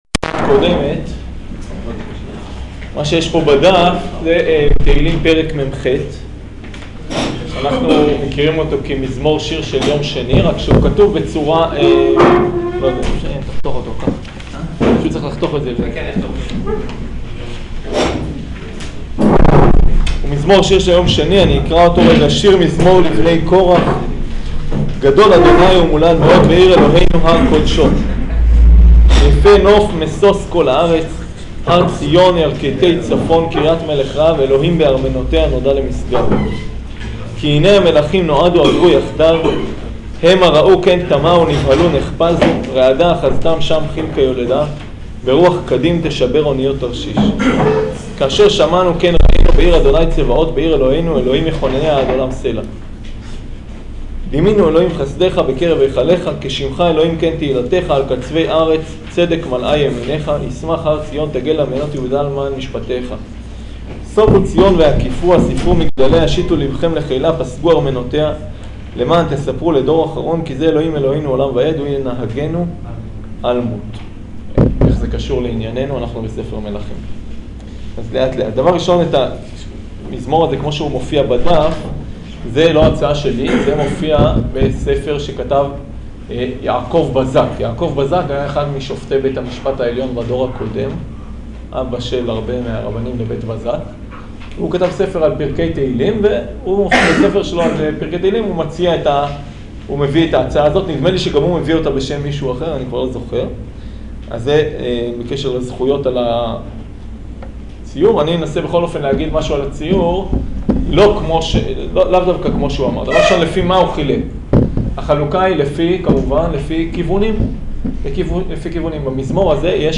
שיעור פרק יא